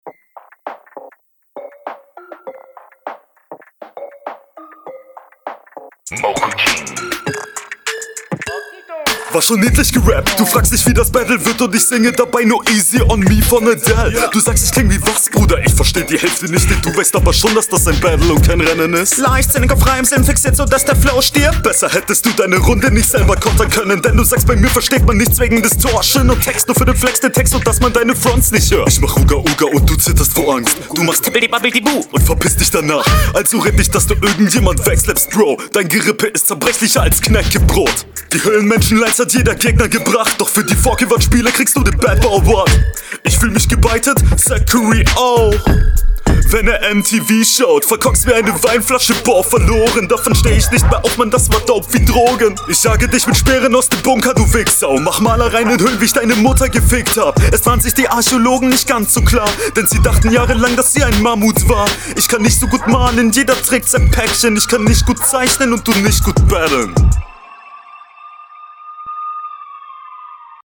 konter eher so meh hibisaiekebuh ist aber gut flow nicht auf level hr ganz ok …